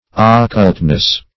Occultness \Oc*cult"ness\, n.